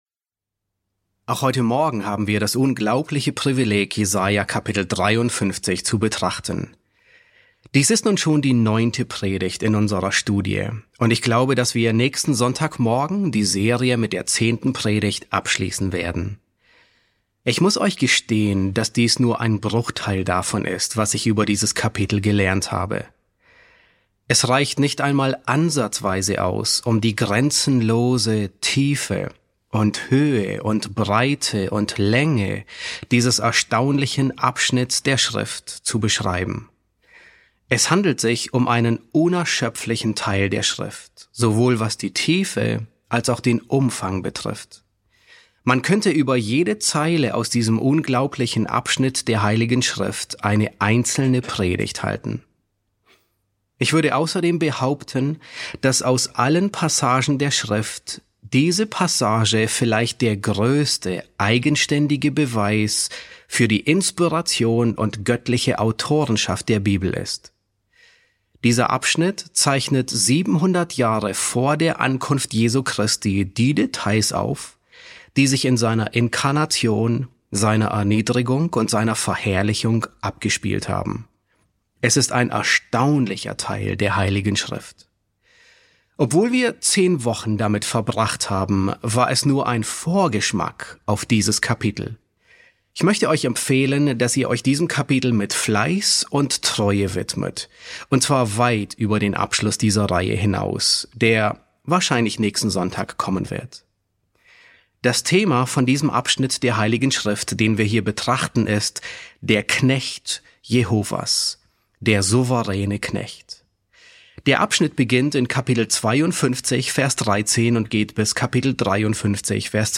S4 F9 | Der souveräne Knecht, Teil 1 ~ John MacArthur Predigten auf Deutsch Podcast